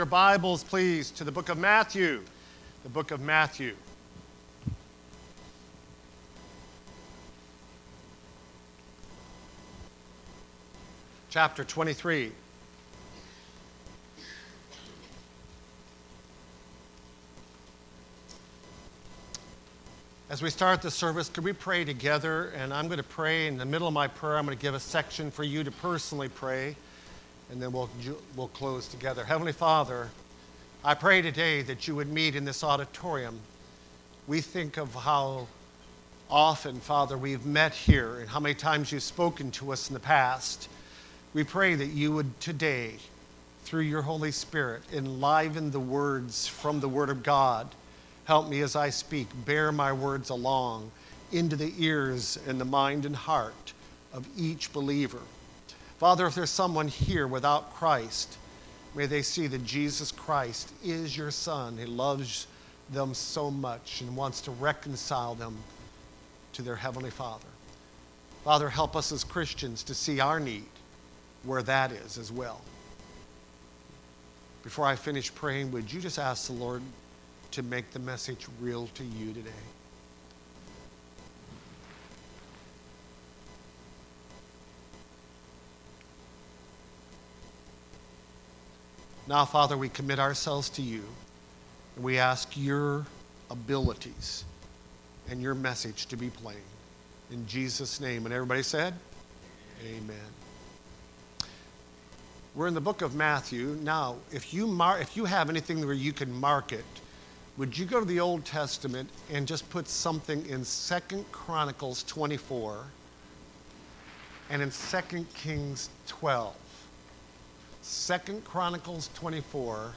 Service Type: Wednesday Prayer Service